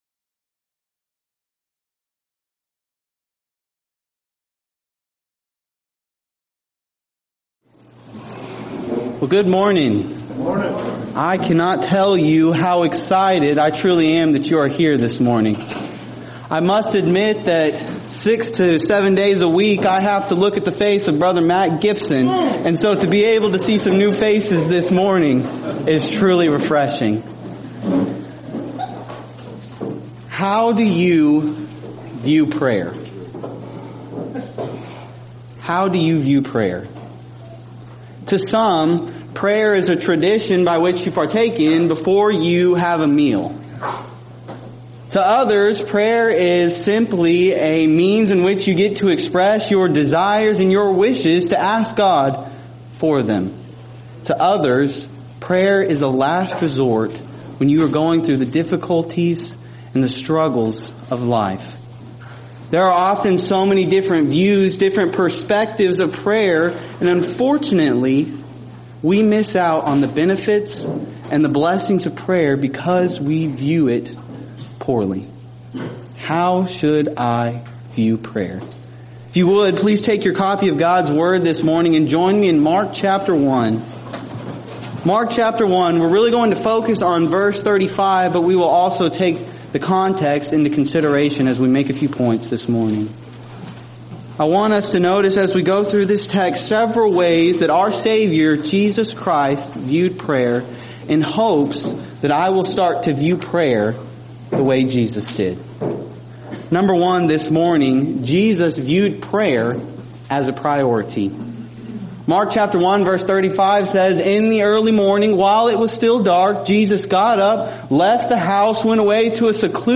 Title: SWSBS Chapel Saturday
Event: 7th Annual Arise: Southwest Spiritual Growth Workshop